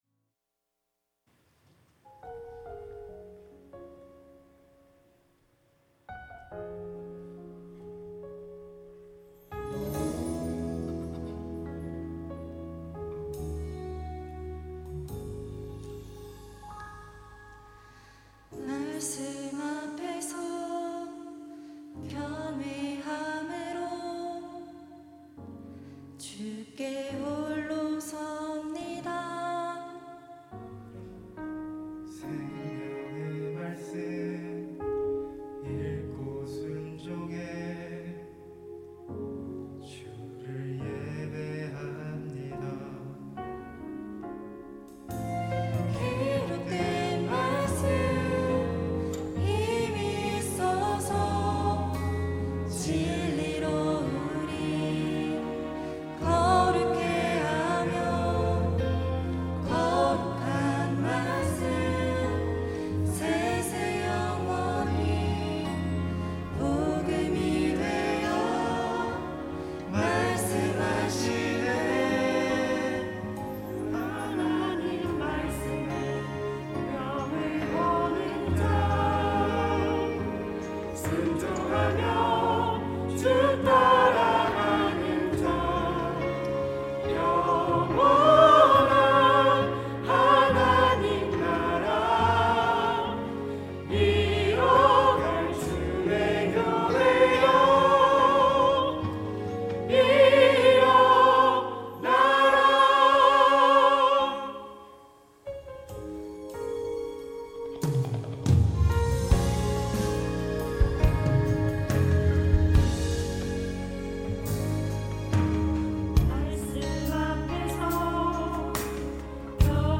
특송과 특주 - 말씀 앞에서
청년부 3팀 리더쉽, 교사